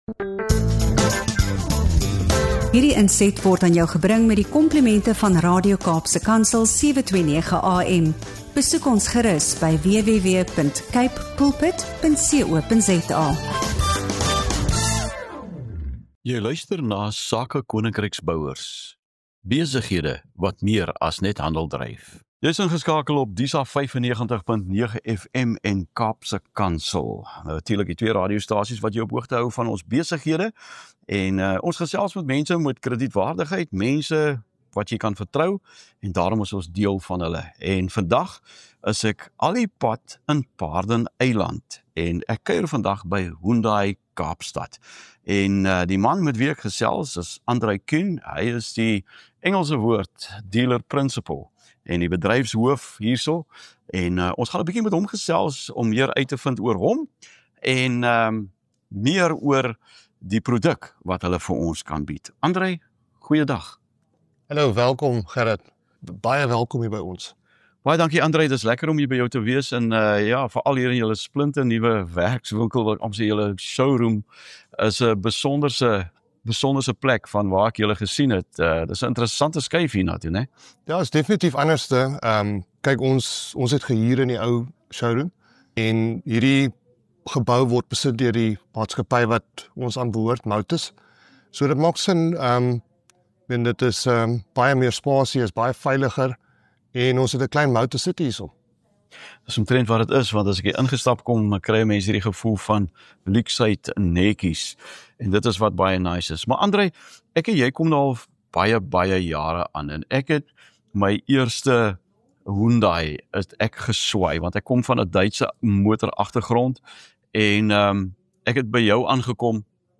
’n Gesprek